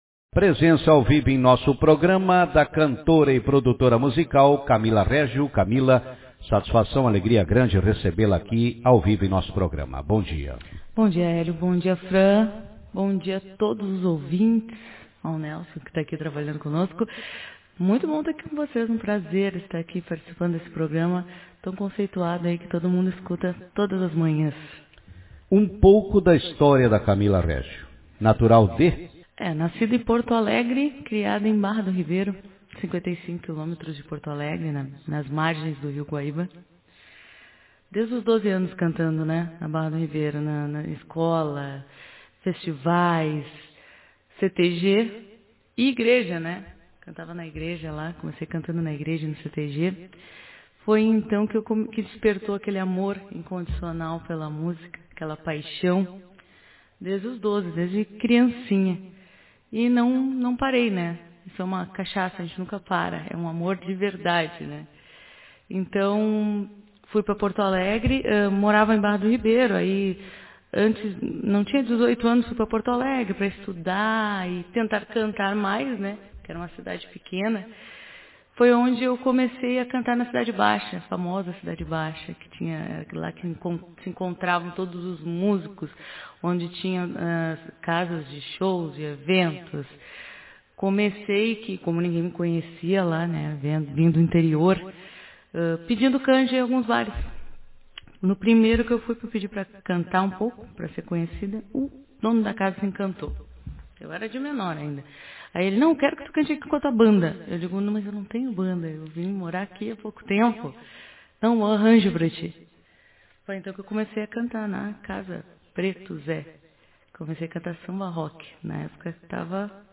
Na manhã desta sexta-feira, 05, participou do programa Café com Notícias